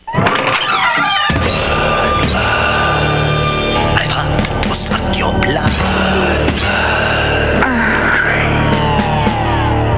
Samples, Stick, Guitars, Piano, Drums, Voices